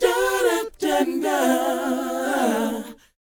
DOWOP G DD.wav